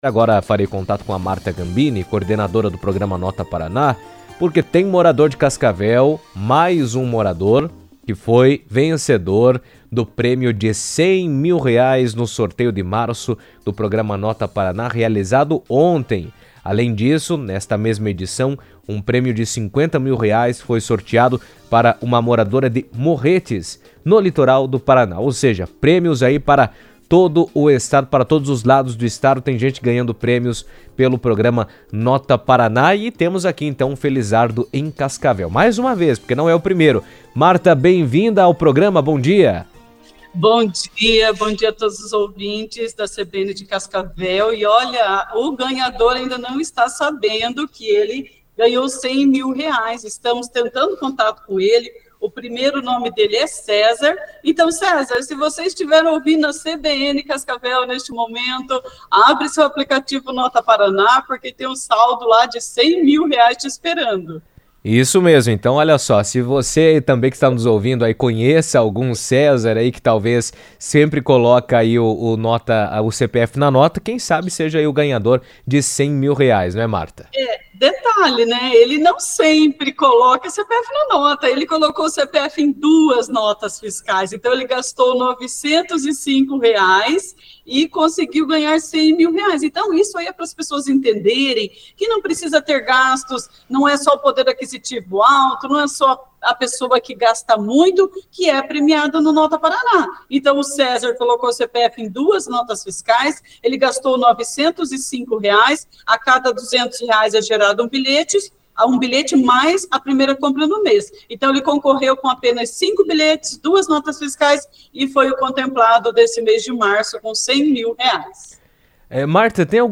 falou sobre o assunto na CBN